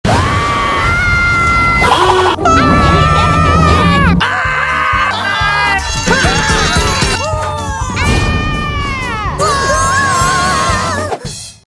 The Best Screams Of MediaToon sound effects free download